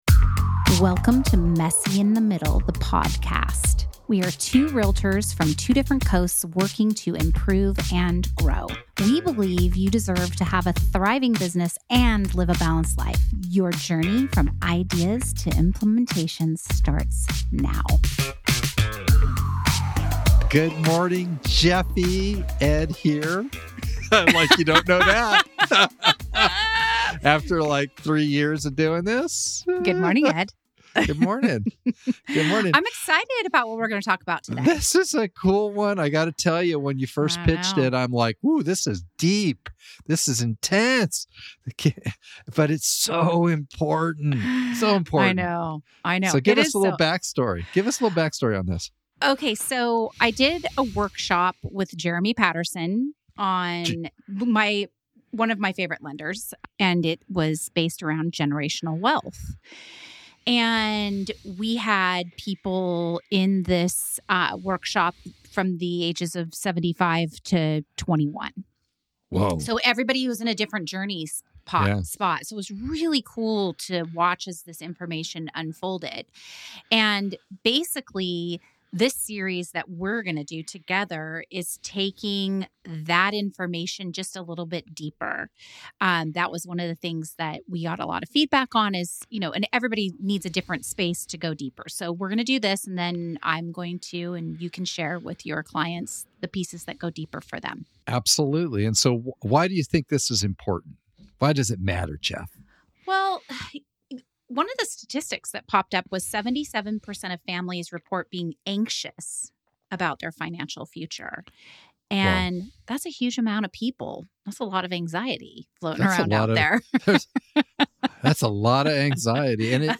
We are two real estate agents from two different coasts, working to improve and grow. We believe you can have a thriving business and live a balanced life.